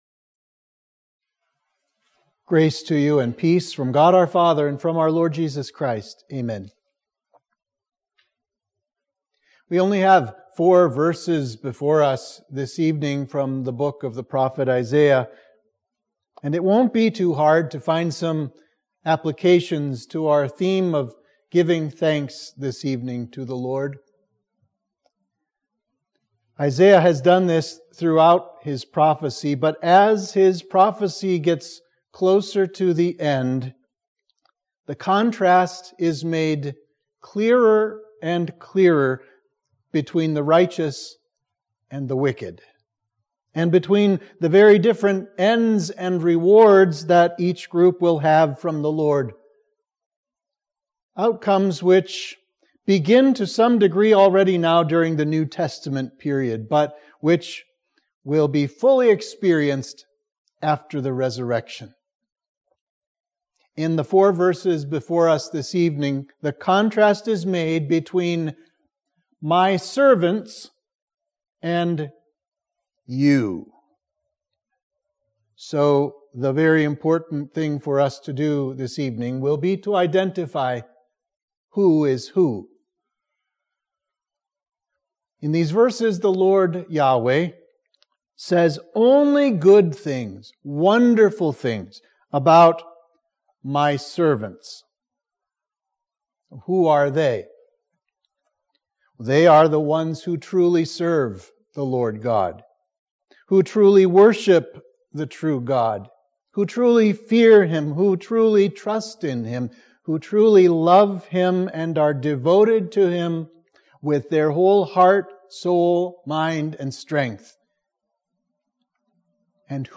Sermon for Thanksgiving Eve